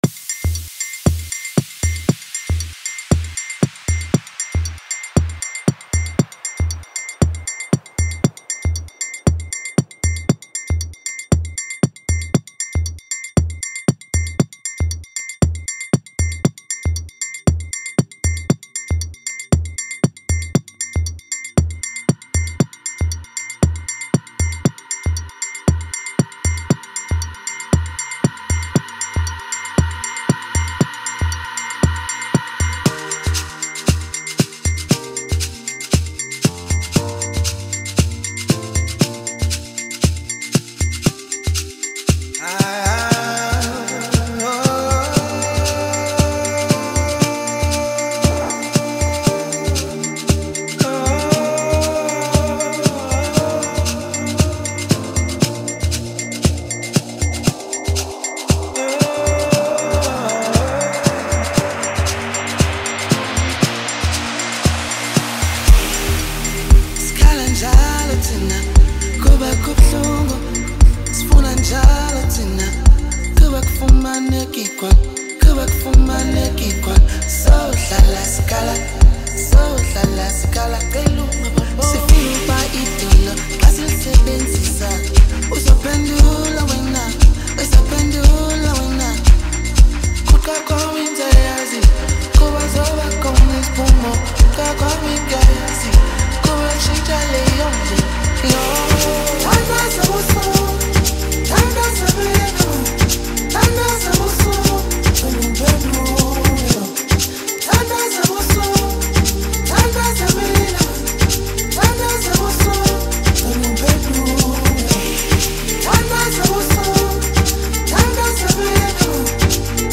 A gifted Amapiano singer and songwriter from South Africa